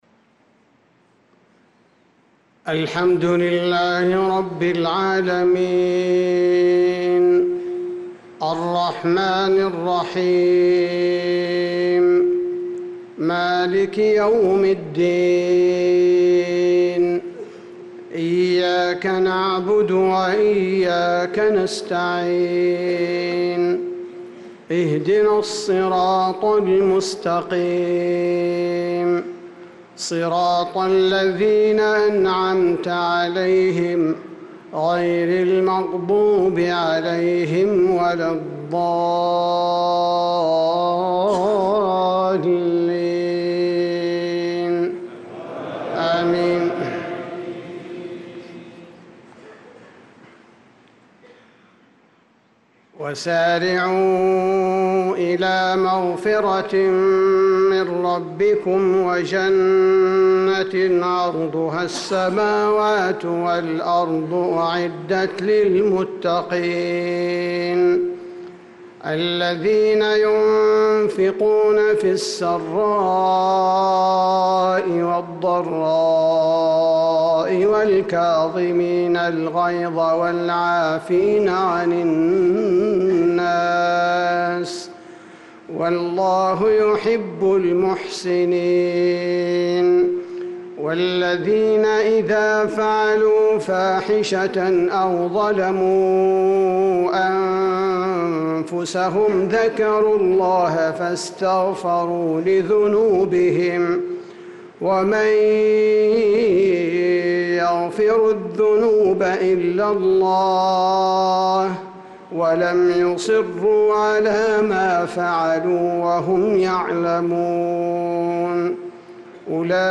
صلاة المغرب للقارئ عبدالباري الثبيتي 29 ذو الحجة 1445 هـ